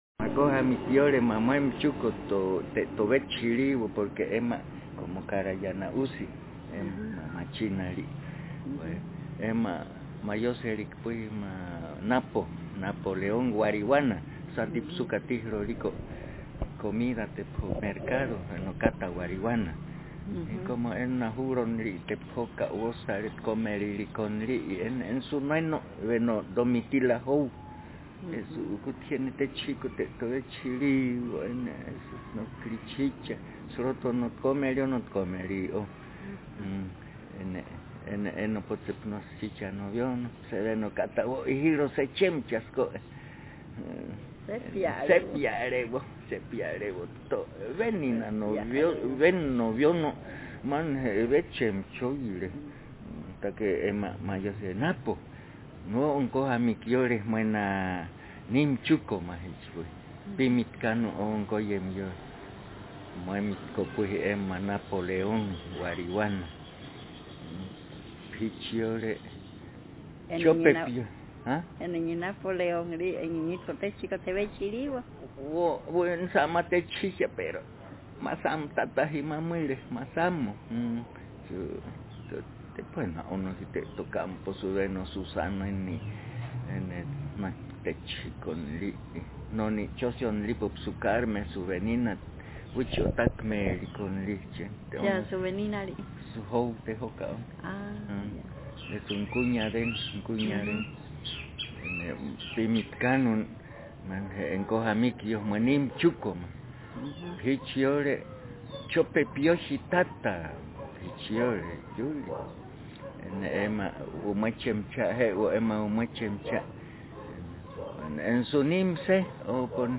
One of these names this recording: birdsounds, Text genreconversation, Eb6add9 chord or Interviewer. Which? Text genreconversation